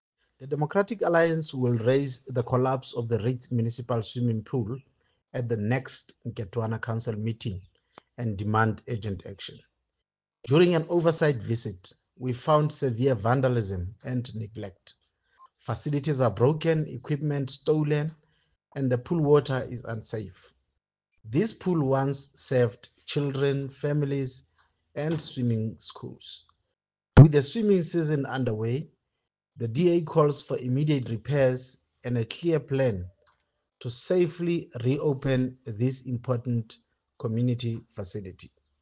English and Sesotho soundbites by Cllr Diphapang Mofokeng and